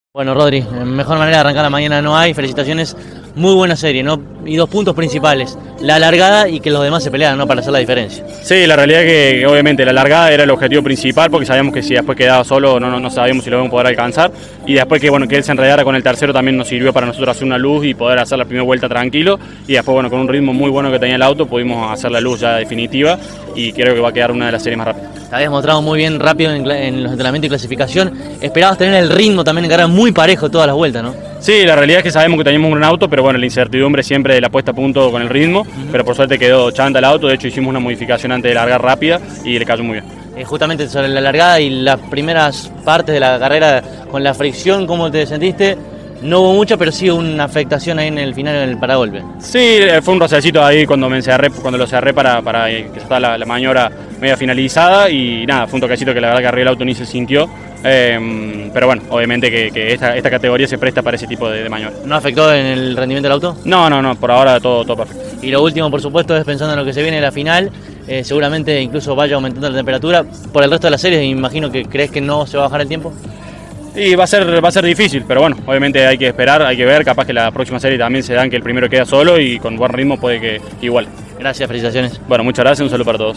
en diálogo con CÓRDOBA COMPETICIÓN: